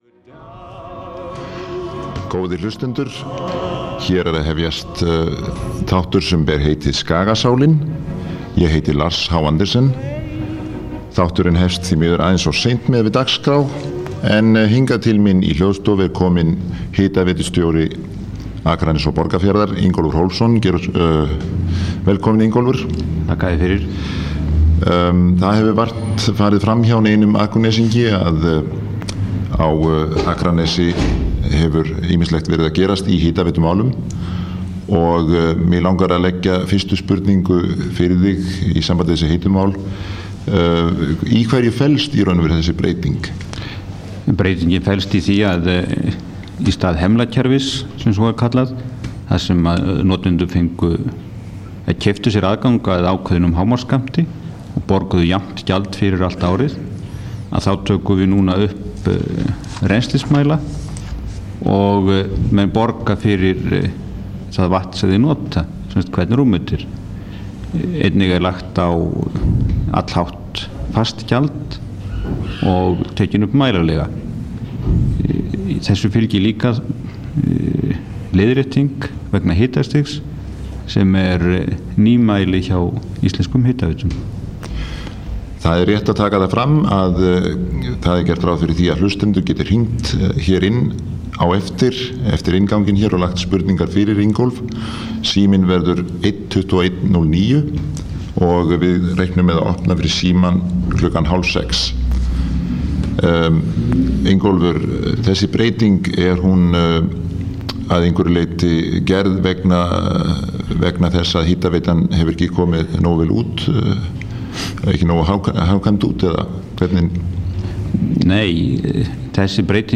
Tónlist og viðtöl úr bæjarlífinu.